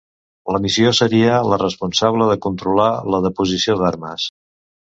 Prononcé comme (IPA)
[kun.tɾuˈla]